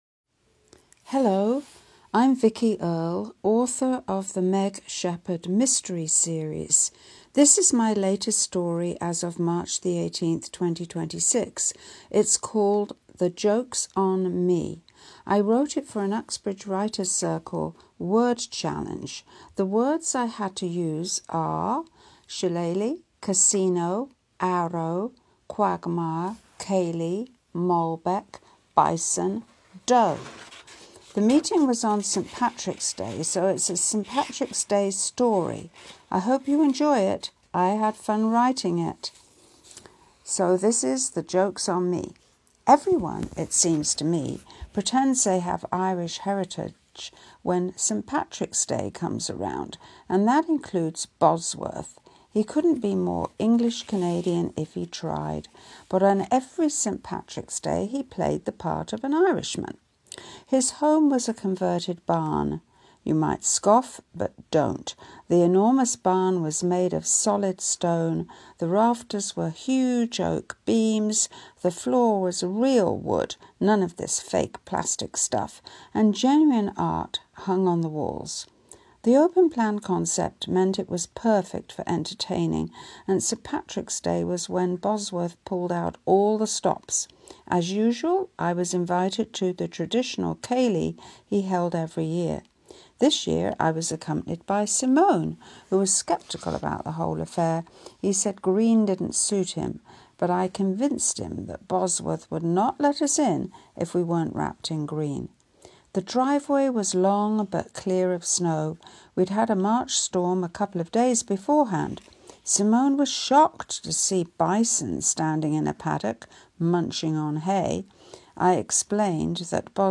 Here is an audio recording of my story, but I've also copied it below. This is the first time I've attempted to record a reading.